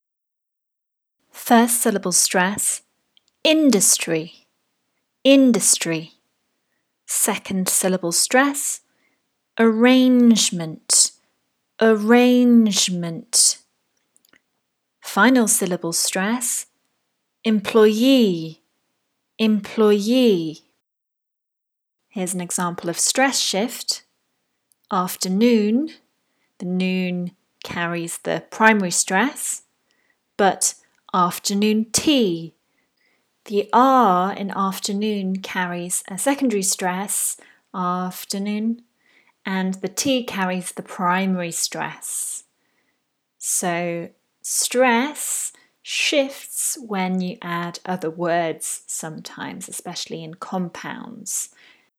5. English words must have one main stress and stress can shift
Sometimes, the stress shifts when a word forms part of a compound. E.g. afterNOON but afternoon TEA.
Stress-shift-2.wav